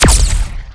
fire_particle4.wav